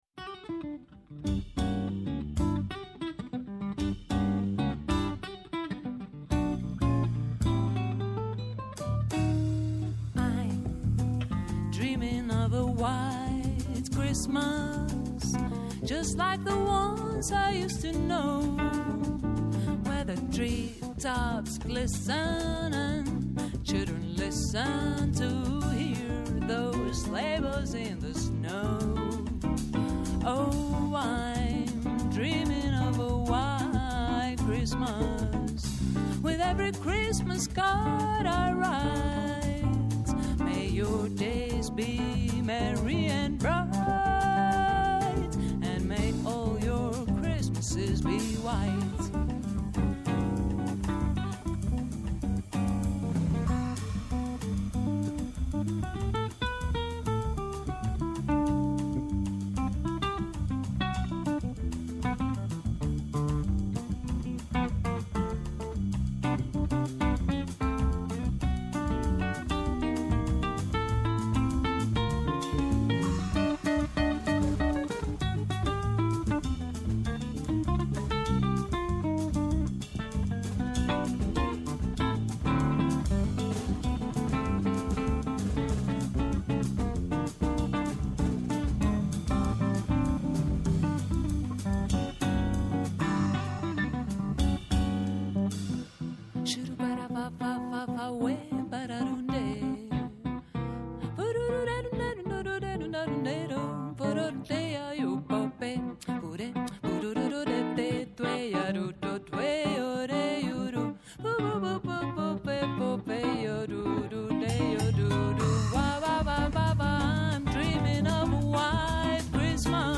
Καλεσμένοι σήμερα στην εκπομπή “Ναι μεν Αλλά” :
-Ο Τάσος Γιαννίτσης, Ομότιμος Καθηγητής Πανεπιστημίου Αθηνών, πρώην υπουργός